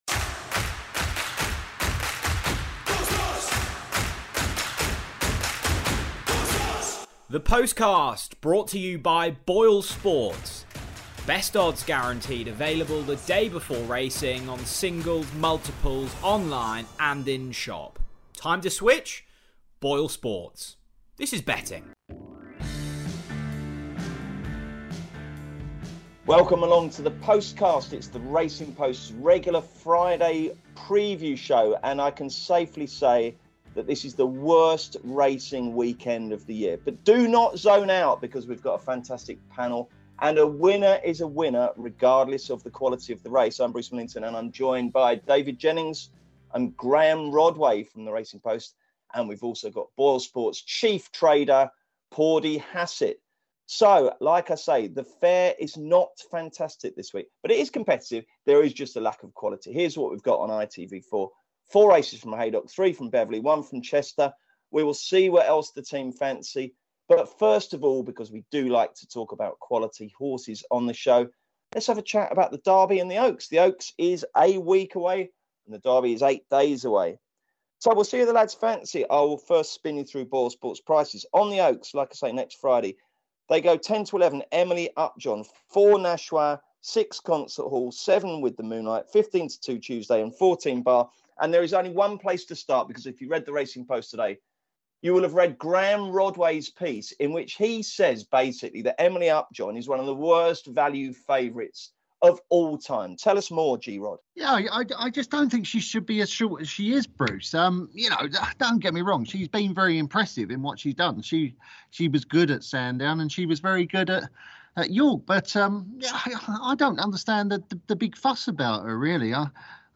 The panel kick things off by looking at the Oaks and Derby, with just a week to go until the action takes place on the Epsom Downs. The lads then preview action from Haydock, Beverley and Chester for what looks a difficult weekend of racing.